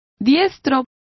Complete with pronunciation of the translation of matador.